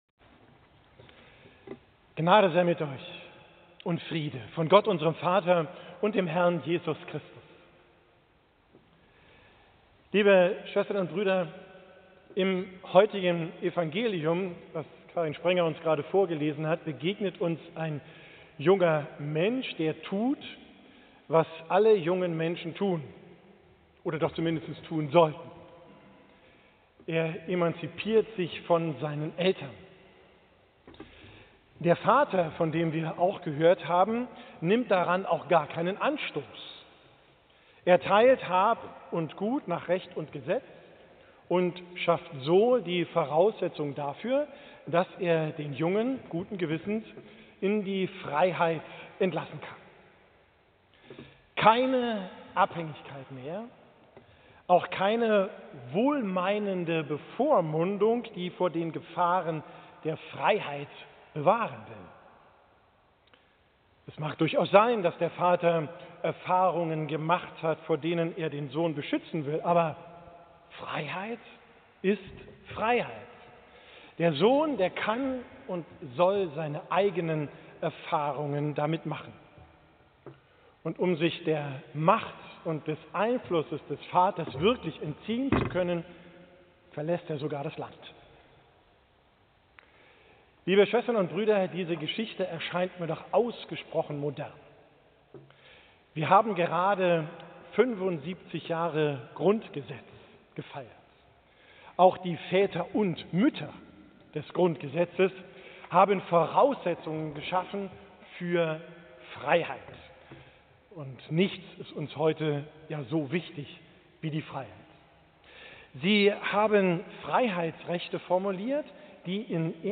Predigt am 3.